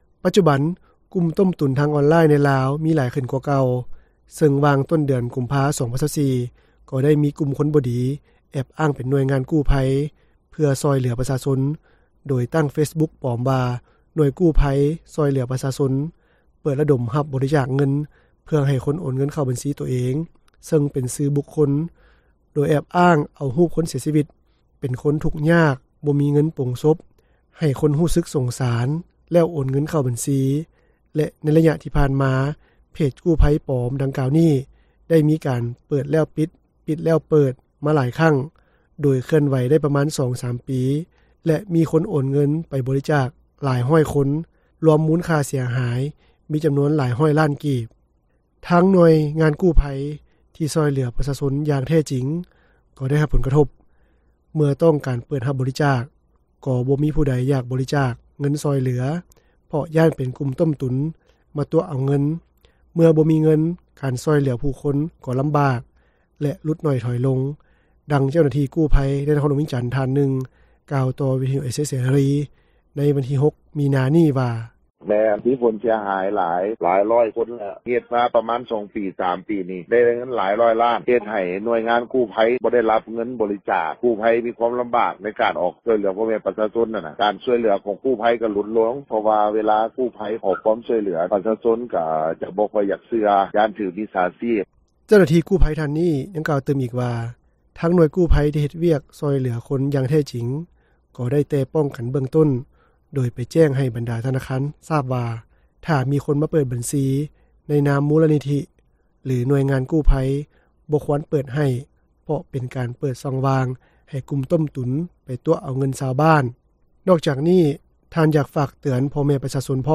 ດັ່ງເຈົ້າໜ້າທີ່ກູ້ພັຍ ໃນນະຄອນຫຼວງວຽງຈັນ ທ່ານນຶ່ງ ກ່າວຕໍ່ວິທຍຸເອເຊັຽເສຣີ ໃນວັນທີ 6 ມີນາ ນີ້ວ່າ: